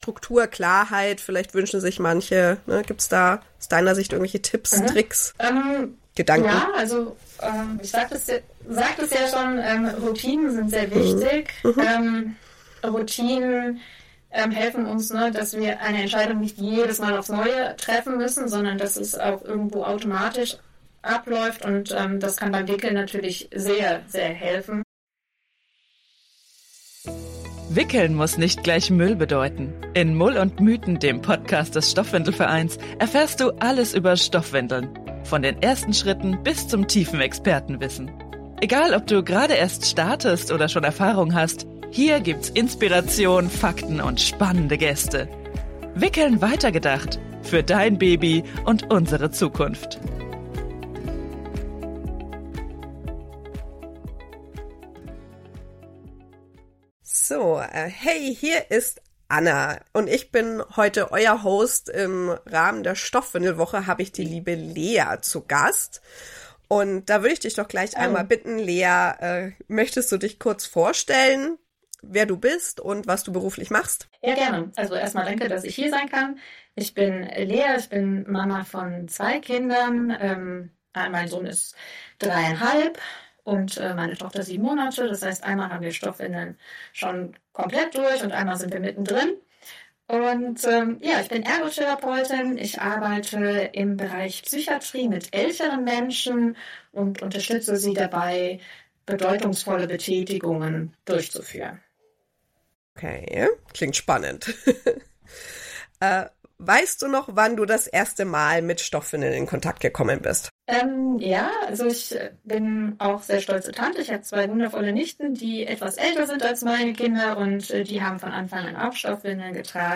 Ein achtsames, inspirierendes Gespräch über Wickeln als bedeutsamen Teil kindlicher Entwicklung – und darüber, was Eltern dabei unterstützen kann.